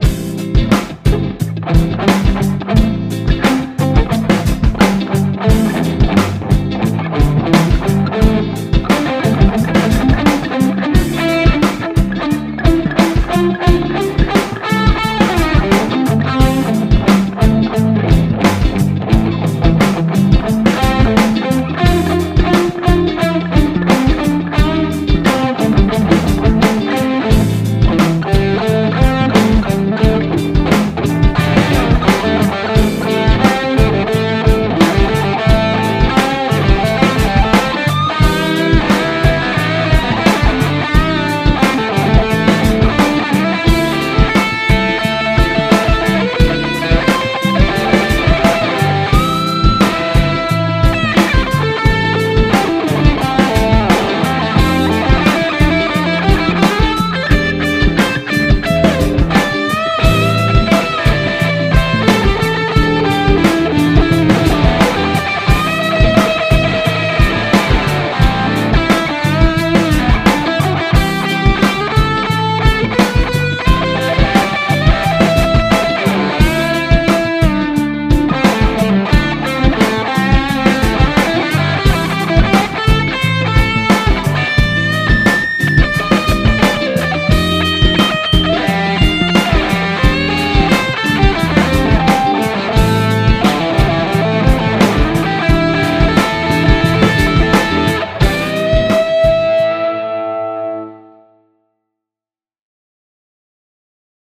Tämä on rootsskaba roots-musiikista kiinnostuneille, joilla ei välttämättä ole (mutta voi myös olla) taitoa tai kokemusta, jotta julkaisukynnys ylittyisi olemassa oleviin (roots, jazz, soolo, bassolinja) skaboihin.
- soita soolosi annetun taustan päälle
- taustan tulee olla sointukierroltaan blues (I-IV-V)- sointuja ja tyyliä soveltaen.
energian voimalla eteenpäin 3 p